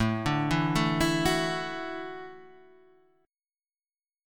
A6sus4 chord {5 5 2 2 5 2} chord